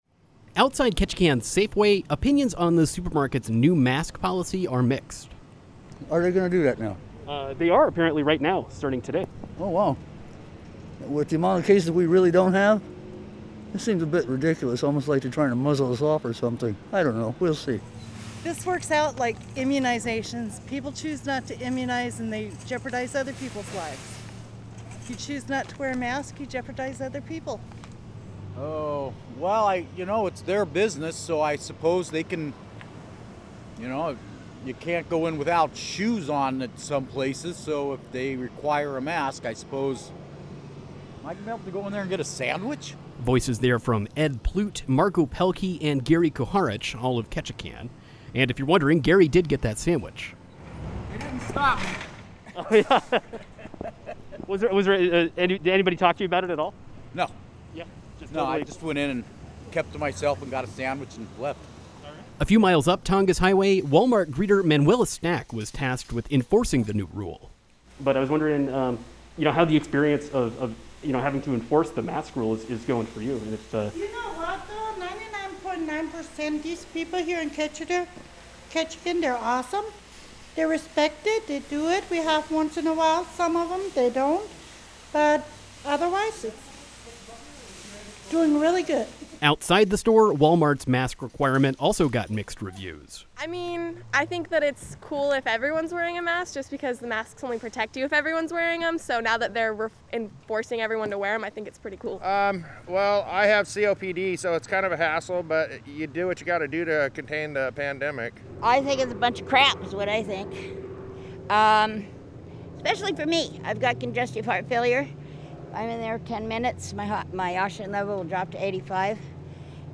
KRBD sent a reporter to skulk around parking lots to see how residents are responding.